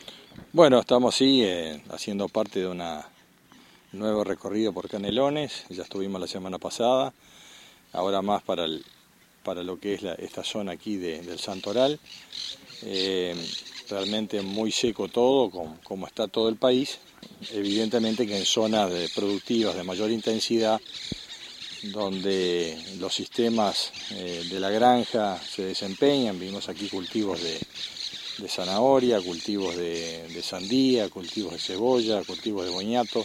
fernando_mattos_ministro_mgap.mp3